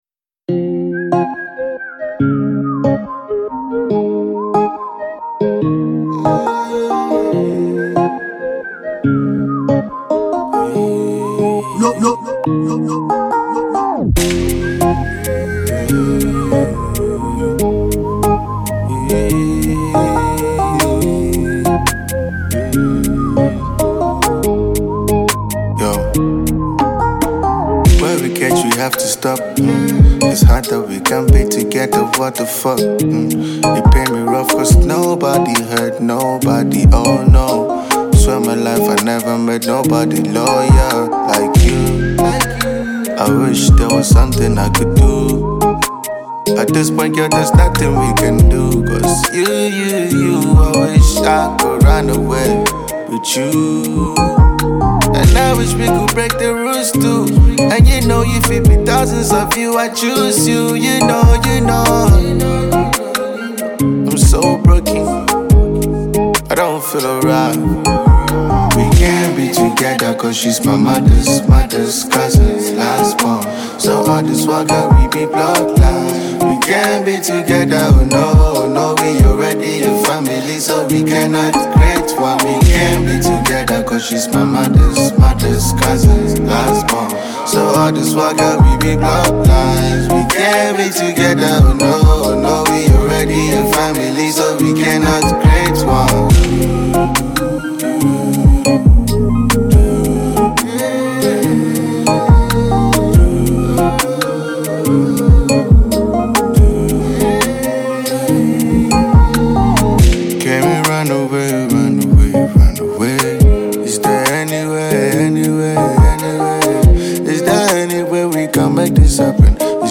a Ghanaian trapper